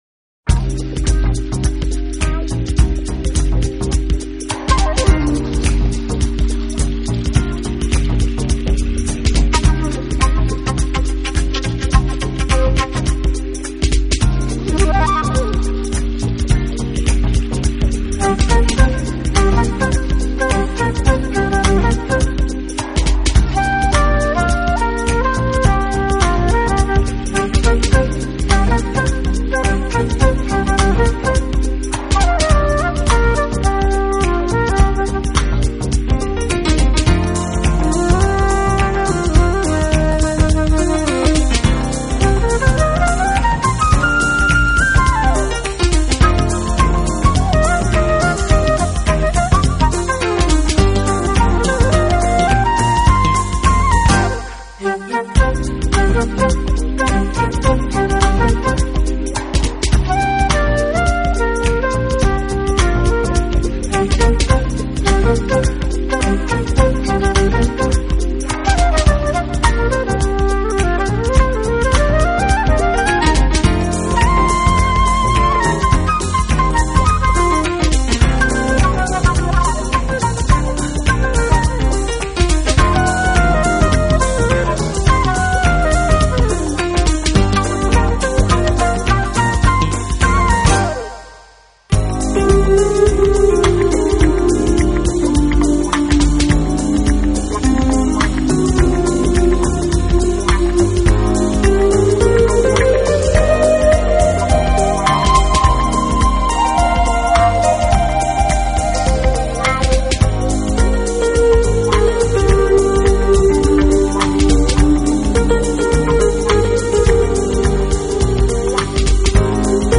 Genre 流派：Jazz
Smooth Jazz/Contemporary Jazz/Crossover Jazz